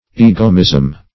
egomism - definition of egomism - synonyms, pronunciation, spelling from Free Dictionary Search Result for " egomism" : The Collaborative International Dictionary of English v.0.48: Egomism \E"go*mism\, n. Egoism.